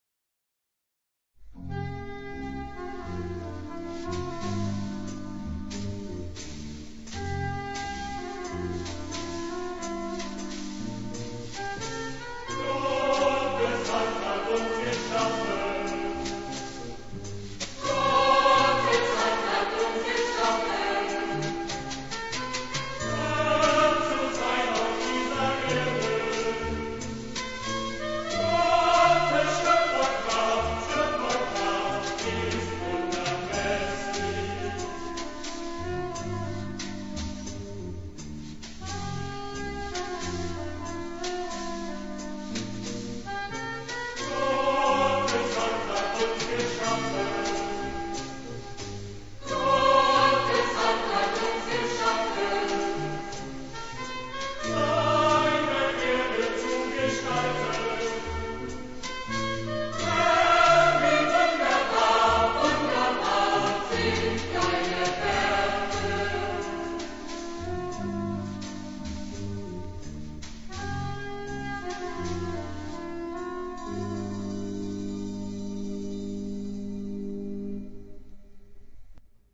Lied ; Sacré
rythmé ; vivant
(1 voix unisson )
Orgue ou Piano OU Instruments ad lib.